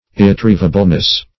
Search Result for " irretrievableness" : The Collaborative International Dictionary of English v.0.48: Irretrievableness \Ir`re*triev"a*ble*ness\, n. The state or quality of being irretrievable.
irretrievableness.mp3